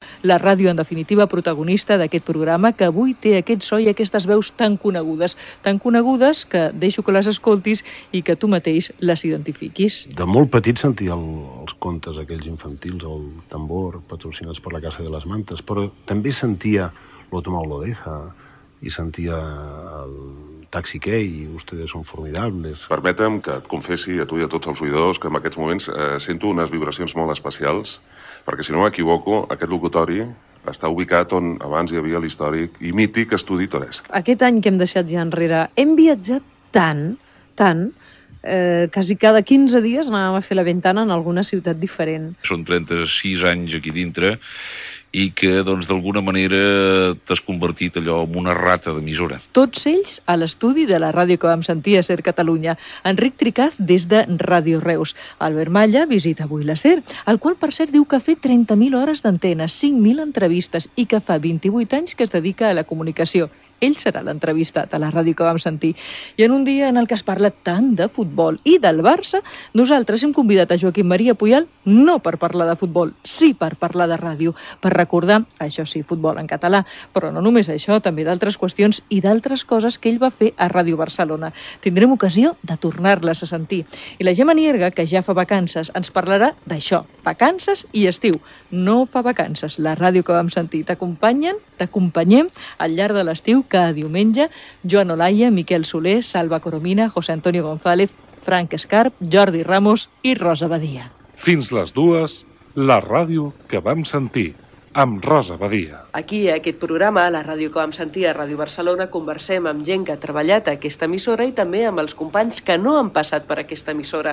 Divulgació
Sèrie de programes amb motiu del 75è aniversari de Ràdio Barcelona.